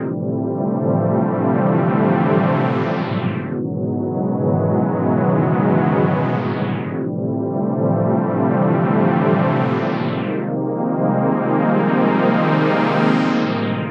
Pad_138_C.wav